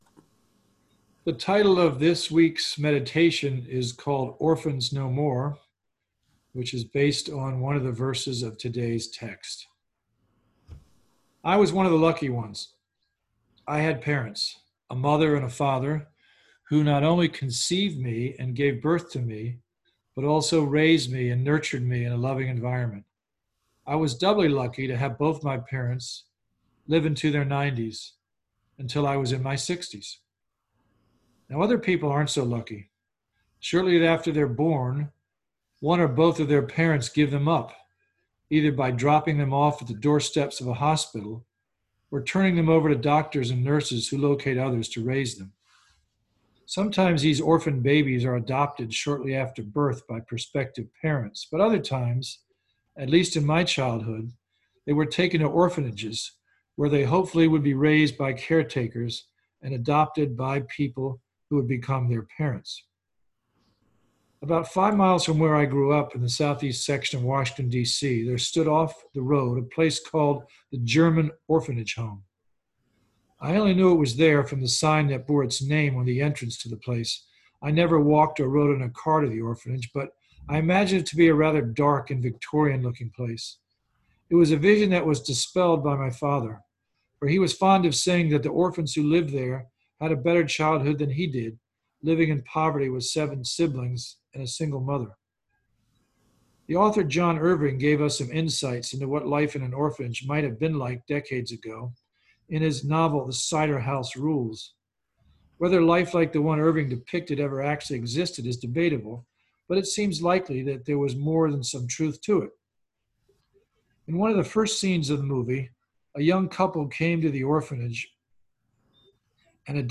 Sunday Sermon
Sermon-5-17-20.mp3